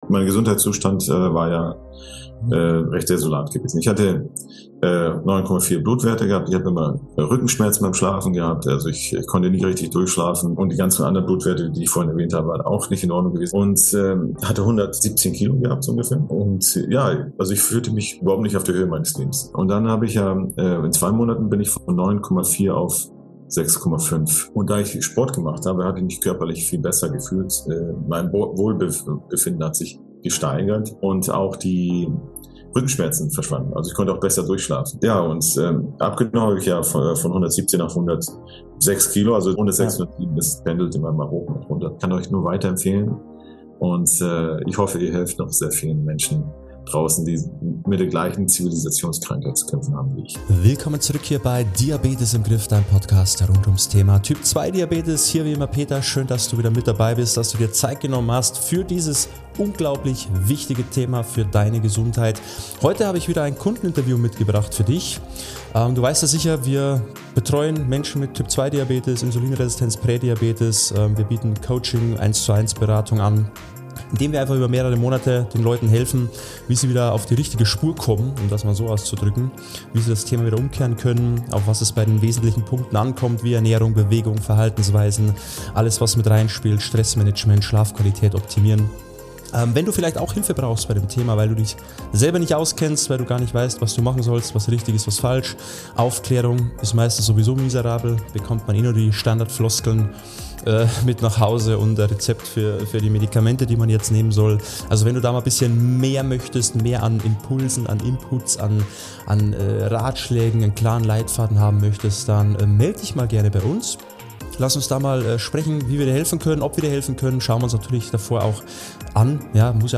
Ein ehrlicher Erfahrungsbericht direkt aus der Praxis